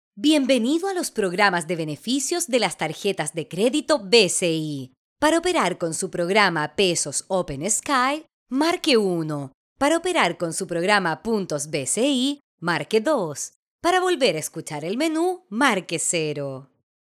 female | South American | Corporate | adult